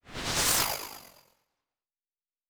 Synth Whoosh 4_2.wav